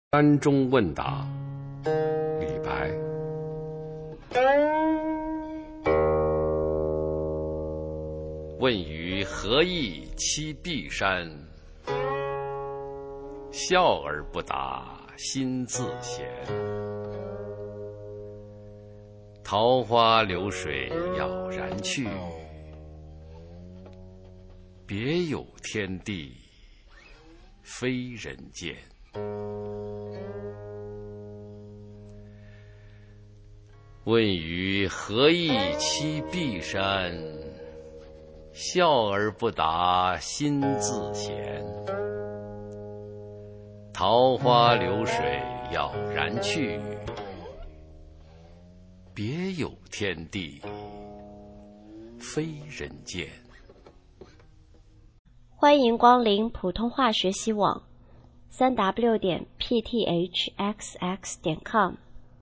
首页 视听 学说普通话 美声欣赏